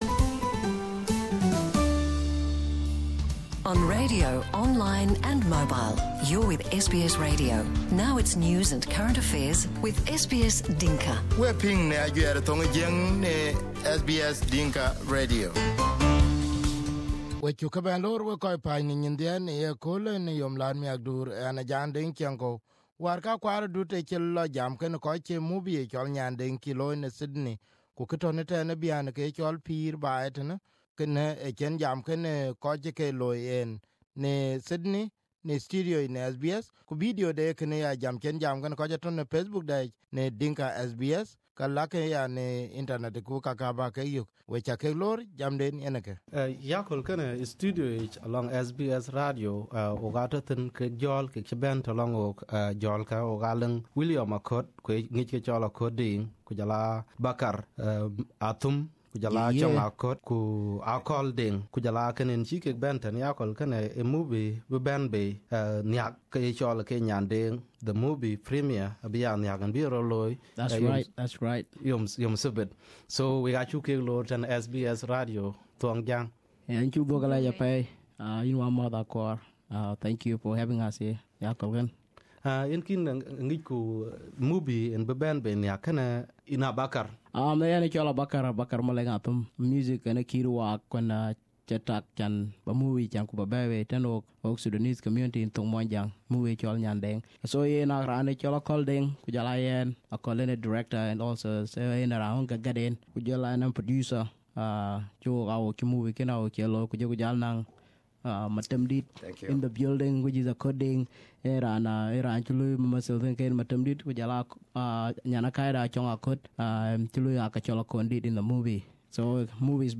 Crew in SBS Studio Source